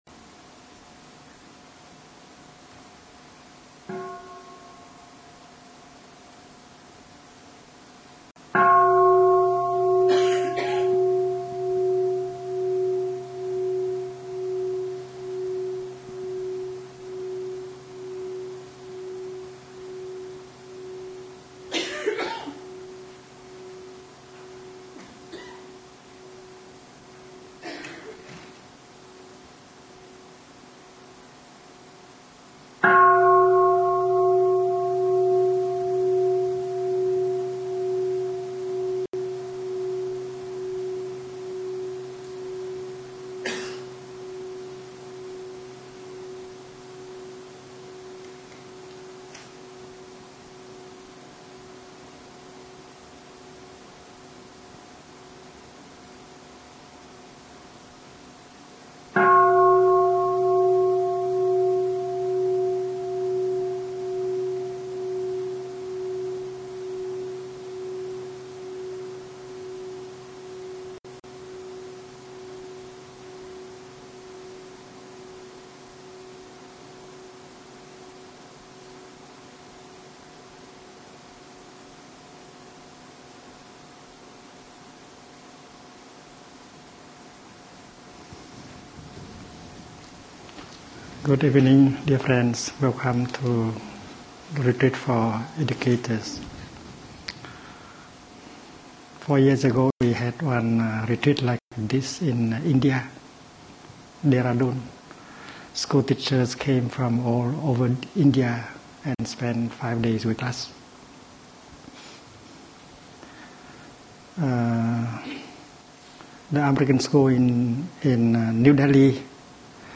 March 30, 2012. 64-minute dharma talk and orientation by Thich Nhat Hanh from The American School in London .